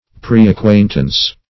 Search Result for " preacquaintance" : The Collaborative International Dictionary of English v.0.48: Preacquaintance \Pre`ac*quaint"ance\, n. Previous acquaintance or knowledge.